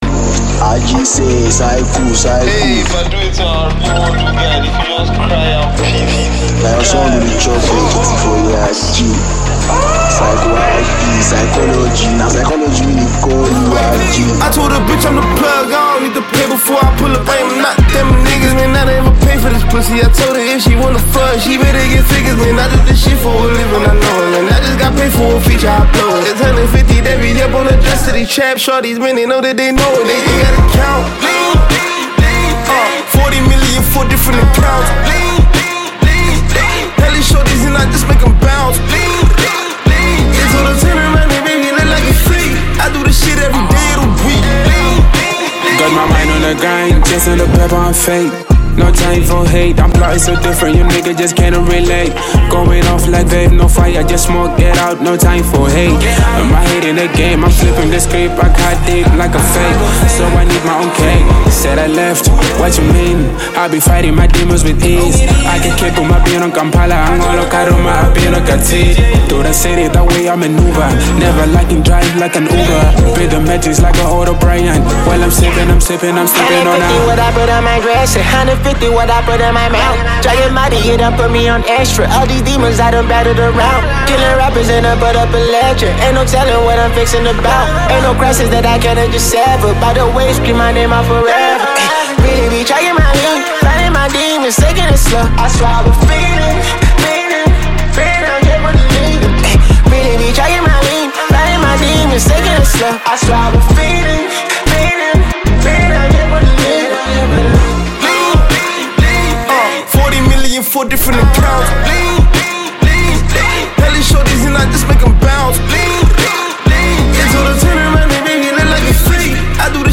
Talented Nigerian music sensation, rapper and songwriter